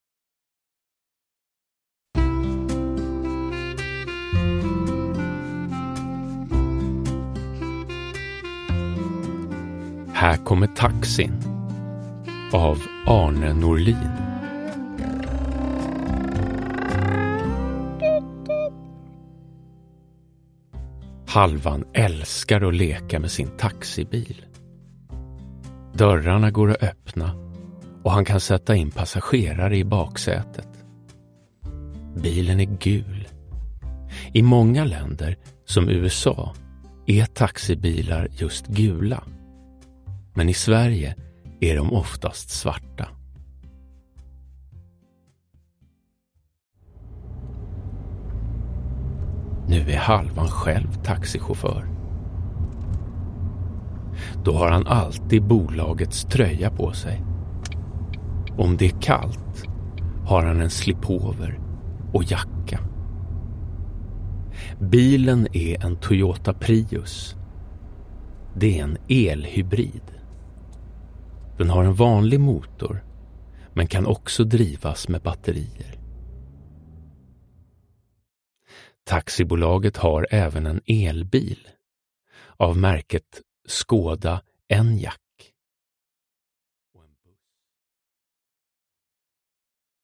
Här kommer taxin – Ljudbok – Laddas ner
Uppläsare: Jonas Karlsson